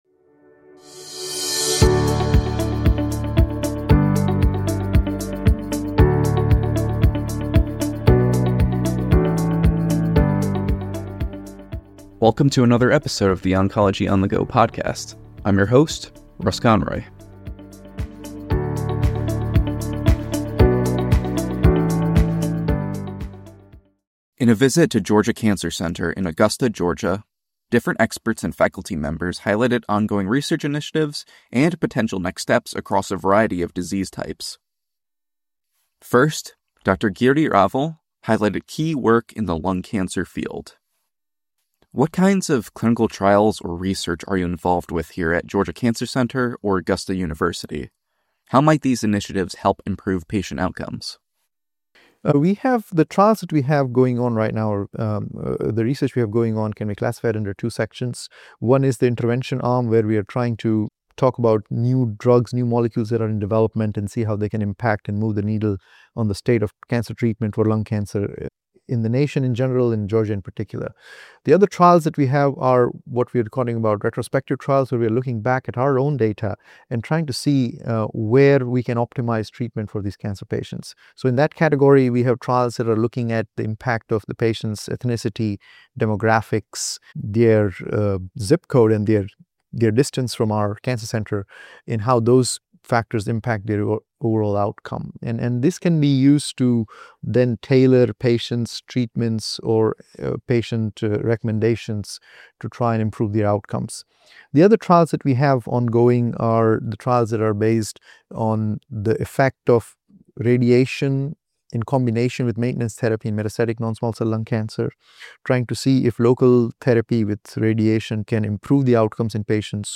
Oncology On The Go is a weekly podcast that talks to authors and experts to thoroughly examine featured articles in the journal ONCOLOGY and review other challenging treatment scenarios in the cancer field from a multidisciplinary perspective. Our discussions also offer timely insight into topics ranging from recent FDA approvals to relevant research presented at major oncology conferences.